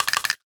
NOTIFICATION_Rattle_01_mono.wav